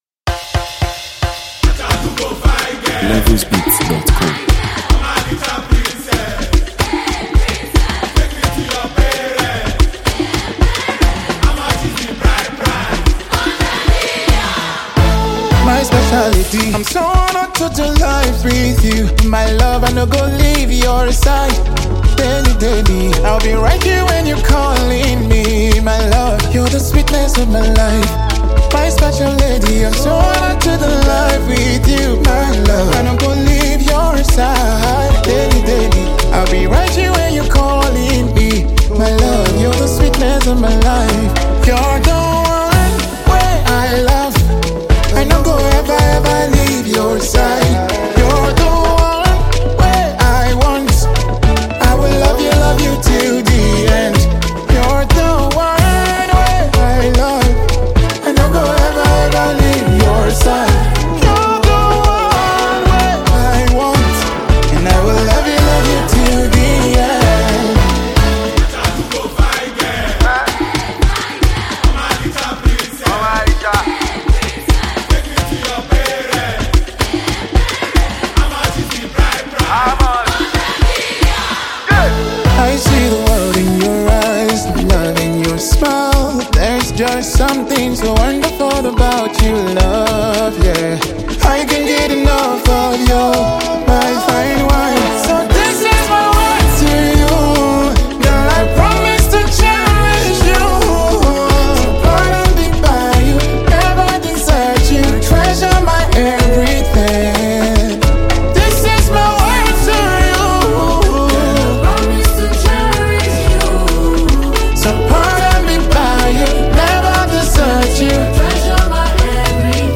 soul-stirring and heartfelt worship song